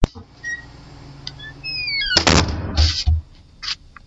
描述：大声关上一扇门
Tag: 响亮 关闭 关闭 门砰 踩住 关闭